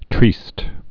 (trēst)